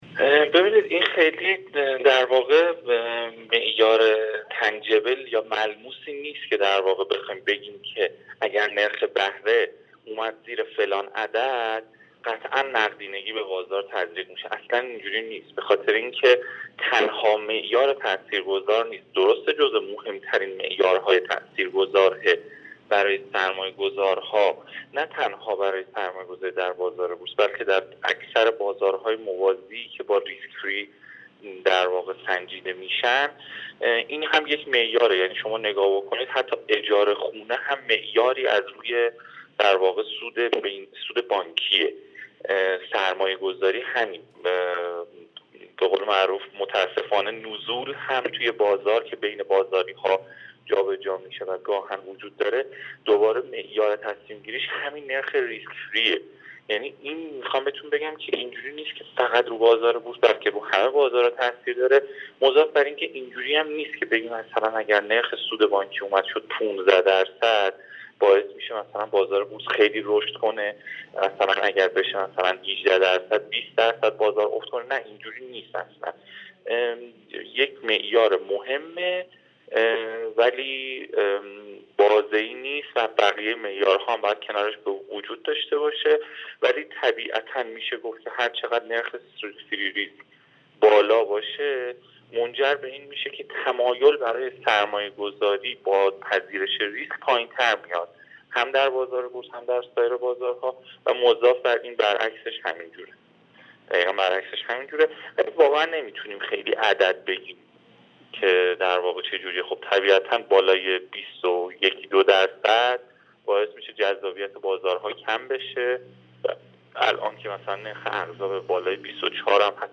کارشناس بازارسرمایه؛